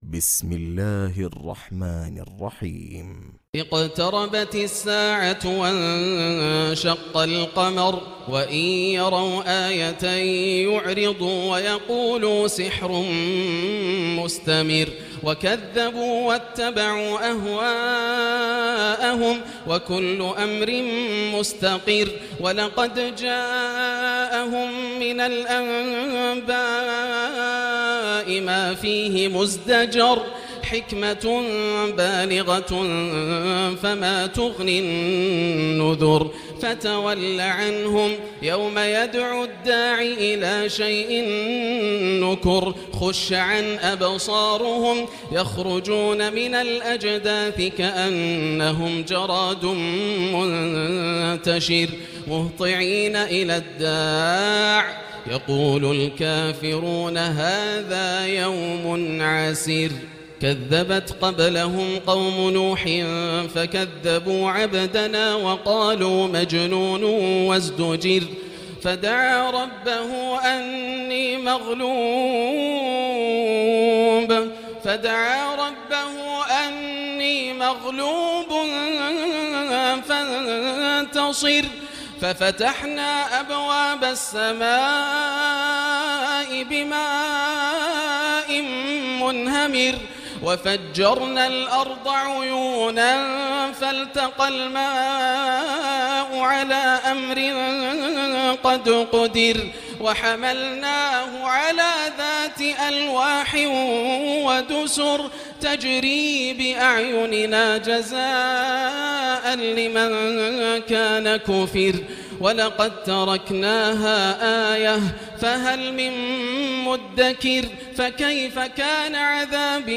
سورة القمر كاملة للشيخ ياسر الدوسري من ليالي رمضان عام 1439هـ Surah Al-Qamar > السور المكتملة > رمضان 1439هـ > التراويح - تلاوات ياسر الدوسري